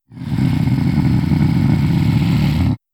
RiftMayhem / Assets / 1-Packs / Audio / Monster Roars / 21.
21. Lurking Growl.wav